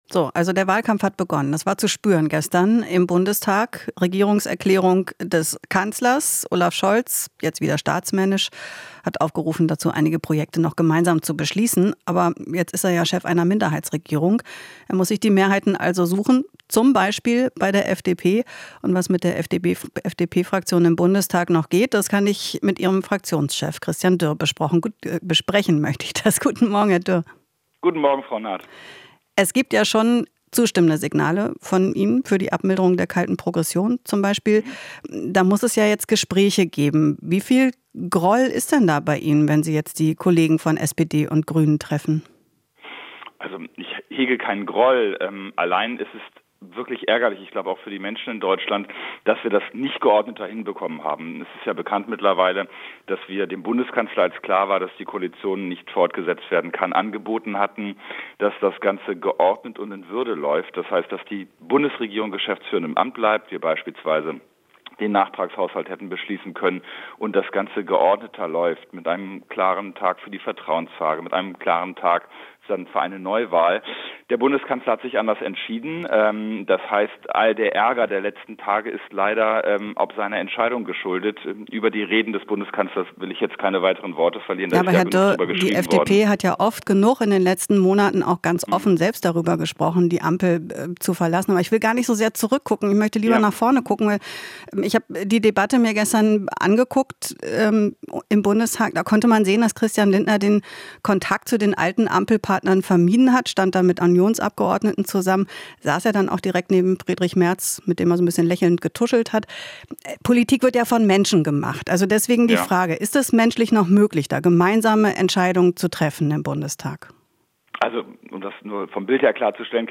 Interview - Dürr (FDP) betont: Sind jetzt eine freie Fraktion